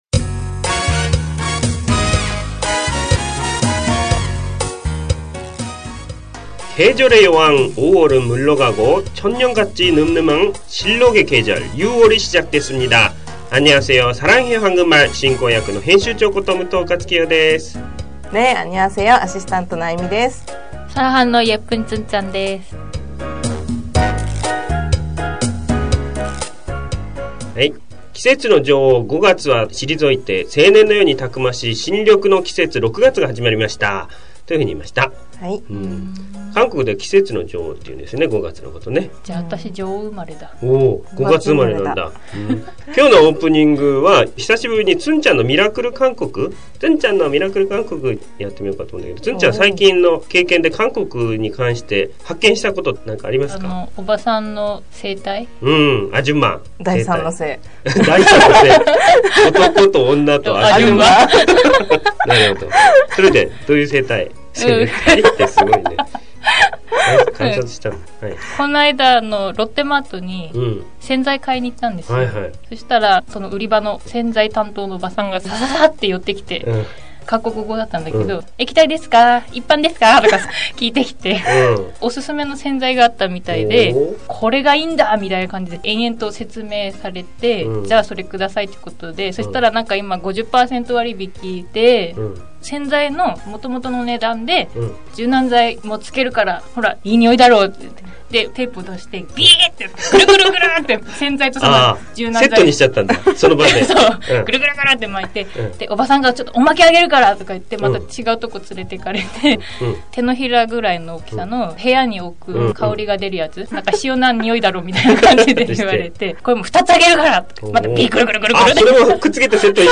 日韓人インタビュー